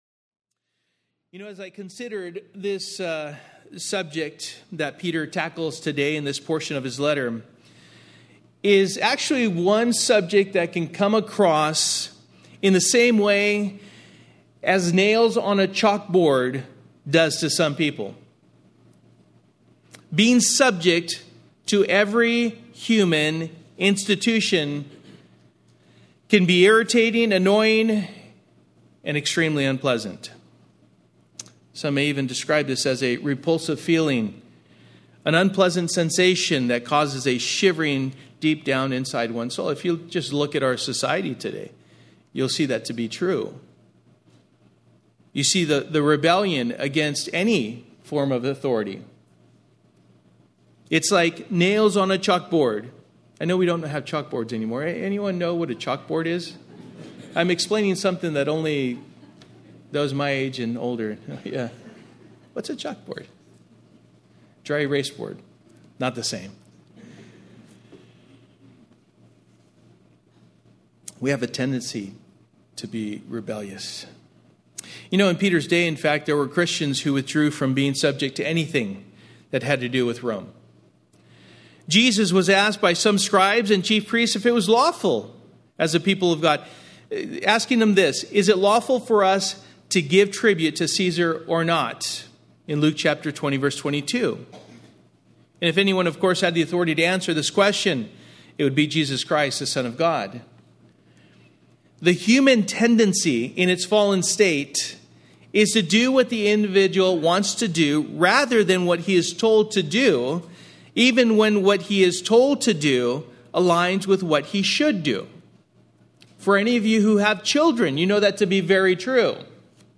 1 Peter Passage: 1 Peter 2:13-25 Service: Sunday Morning « Psalm 74 Day 2